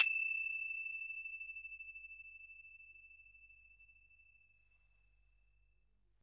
Z Twinkle.wav